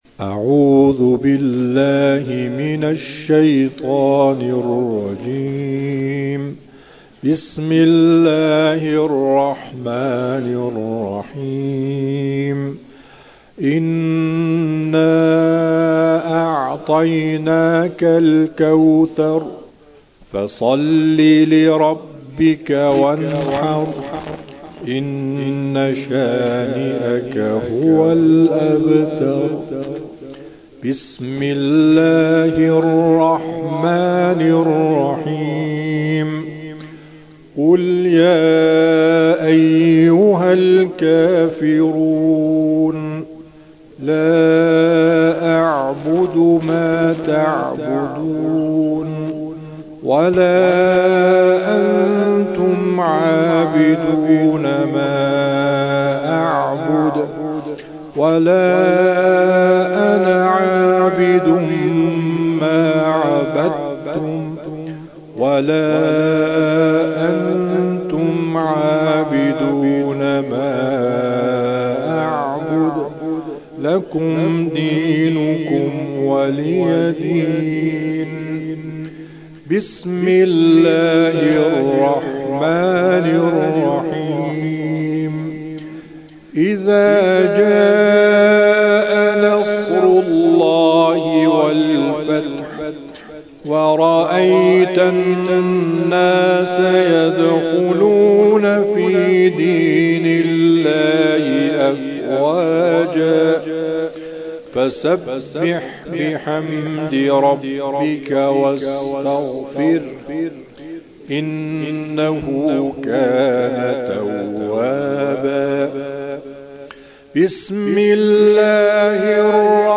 هم‌خوانی